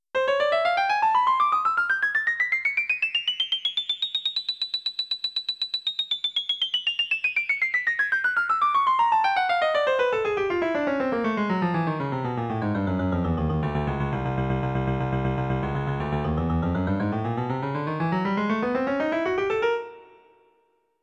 Melodic contour from a sine wave
# It maps the sine function to a melodic (i.e., pitch) contour.
sinemelody.wav